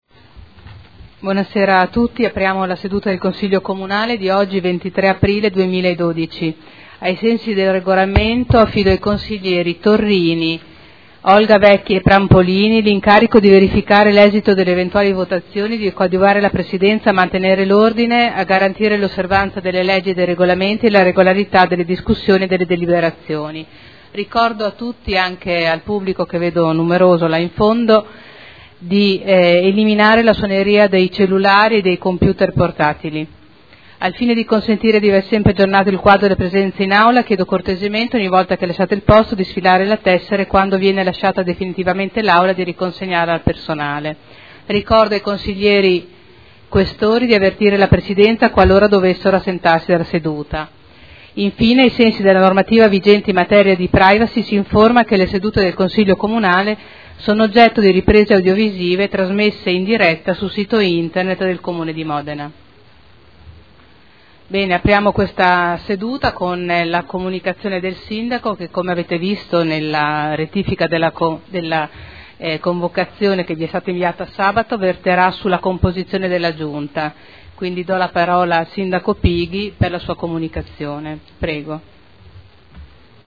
Presidente — Sito Audio Consiglio Comunale
Seduta del 23/04/2012. Apertura lavori e anticipazione della comunicazione del Sindaco sulla Giunta.